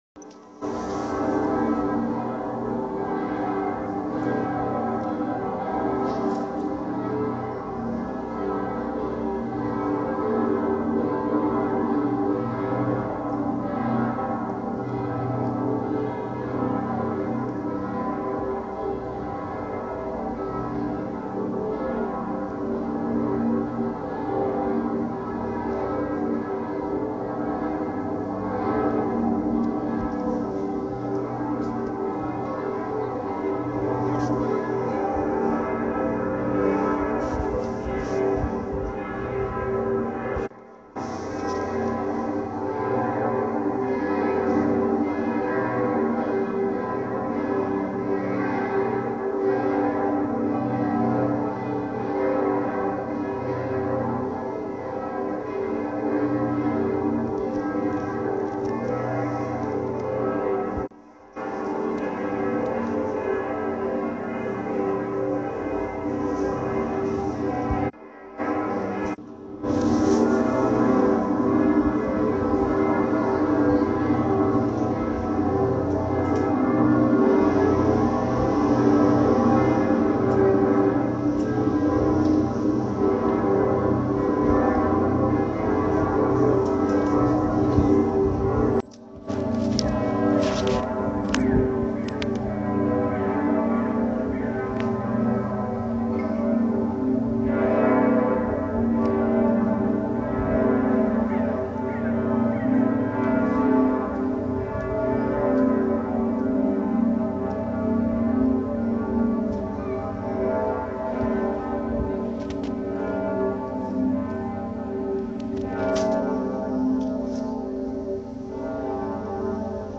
Einige weitere Hörbeispiele zu verschiedenen Glocken:
Kölner Dom, Läuten freitags 19h [3.132 KB]